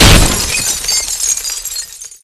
break_glass.ogg